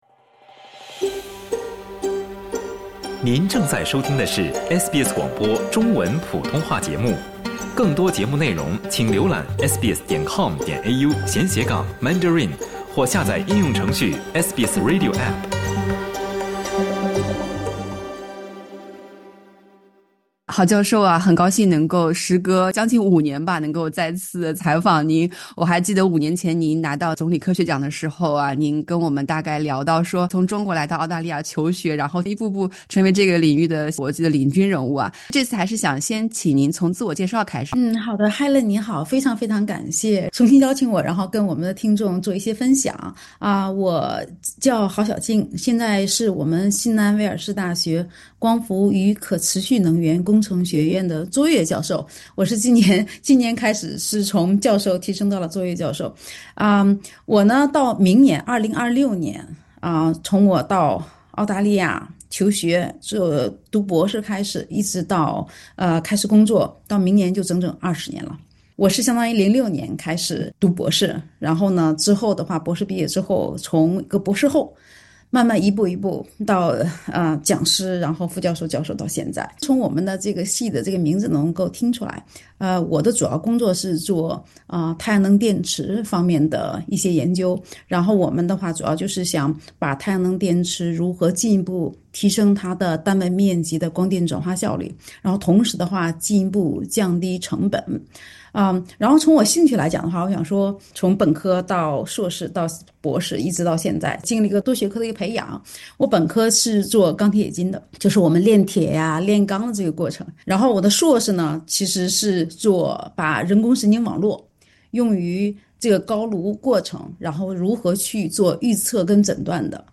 SBS 普通话电台
她在接受SBS中文普通话节目采访时分享，团队致力于提升太阳能电池效率并降低成本，重点研究基于晶硅的叠层太阳能电池。